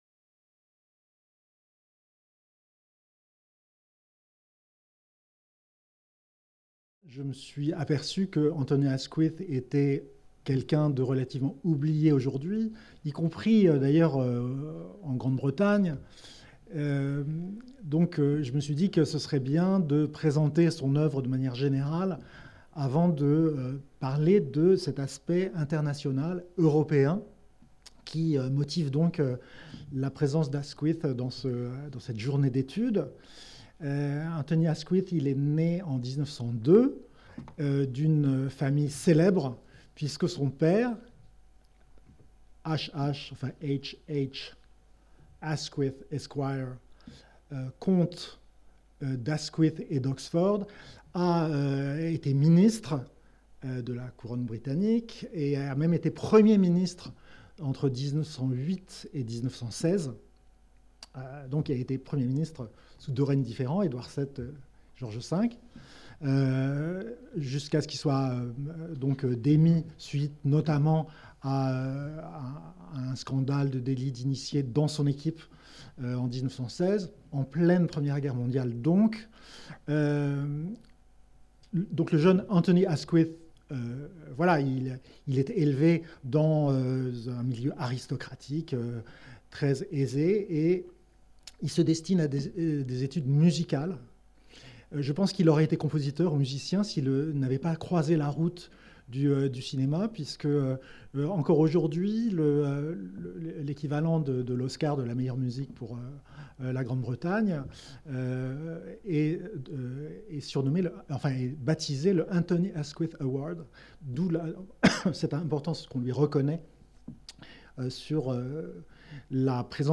Cette communication a été filmée dans le cadre d'une journée d'étude du LASLAR consacrée au cinéma britannique.